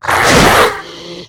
monstermiss.ogg